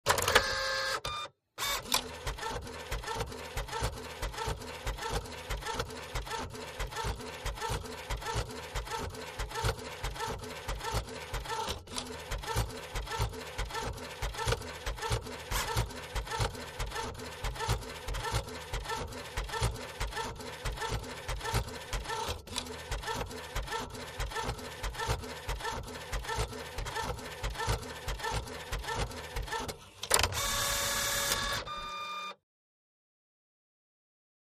Printer; Ink Jet Printer Quickly Prints Out Document.